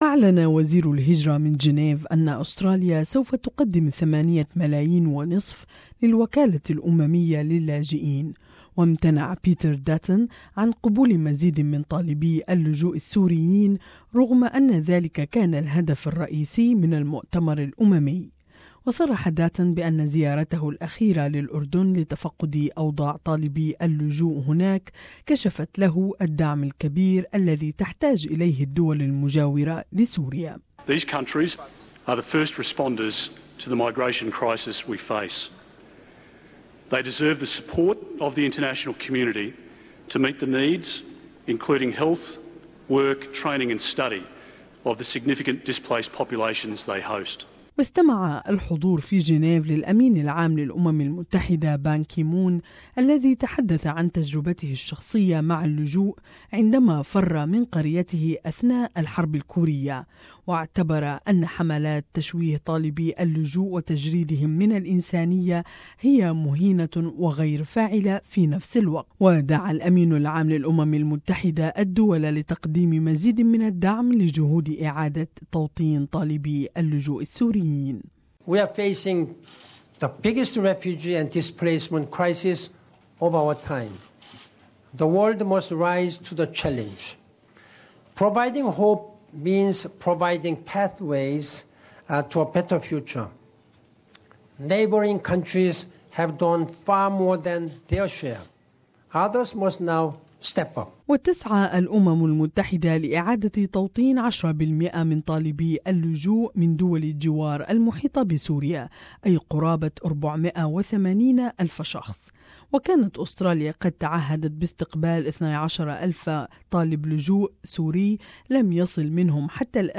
But he has not offered any additional placements, which was one of the international conference's major goals. More in this report